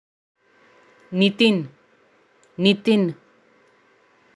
इस शब्द का उच्चारण कैसे करे (Pronunciation of Nitin) = नितिन